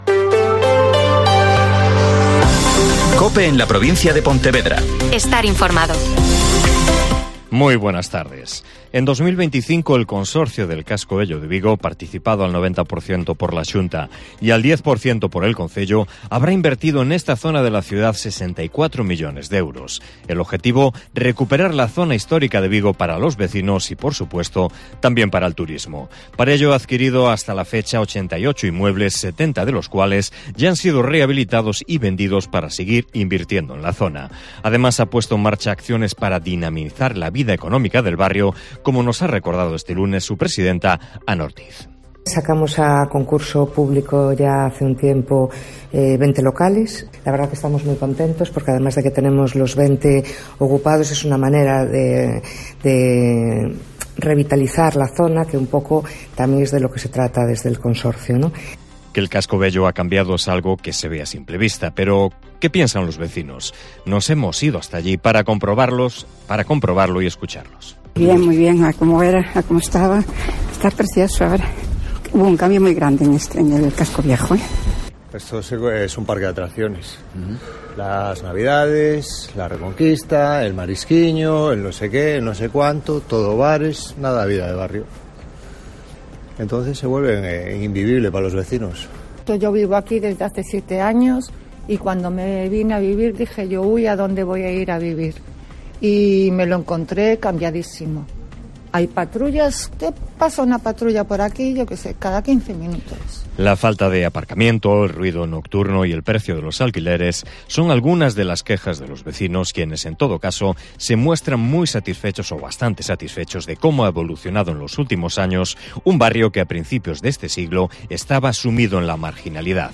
Mediodía COPE en la Provincia de Pontevedra (Informativo 14:20h)